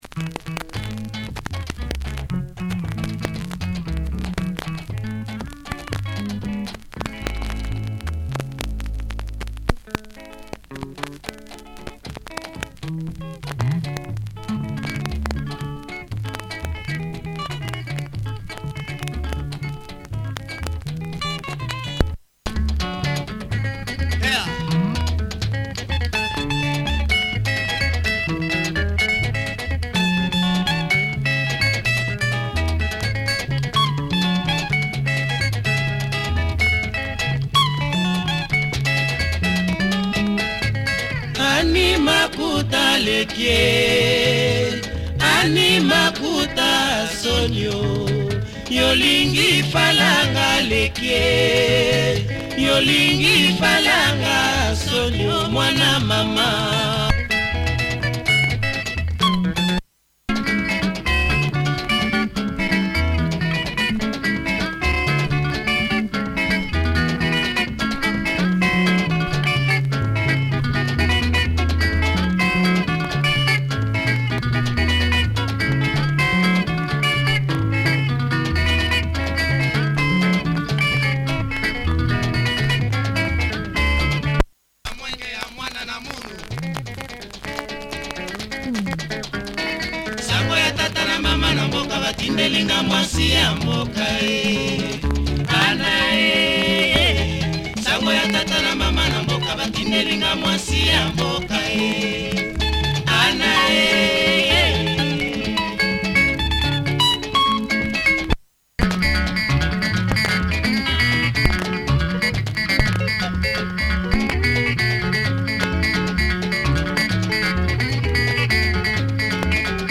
Crazy guitar on this Lingala cut, sought after label.